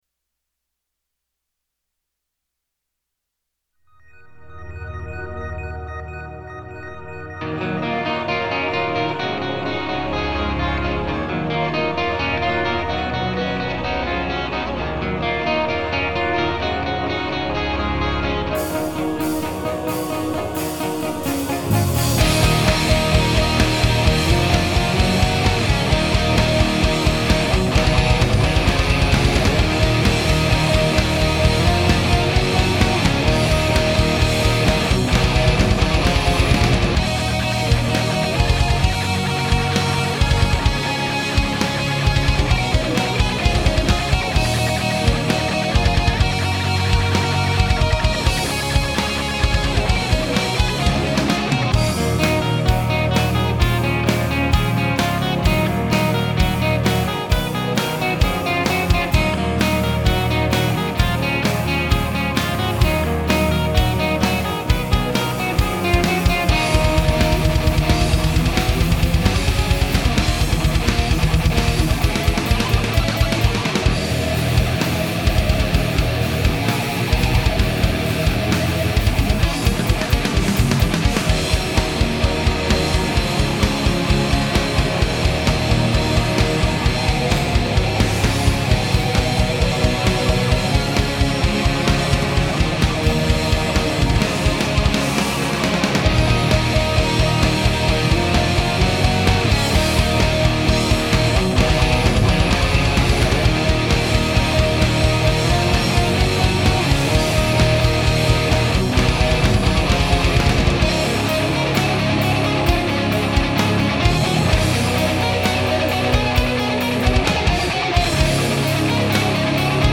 Mastered to somewhere around -13 RMS.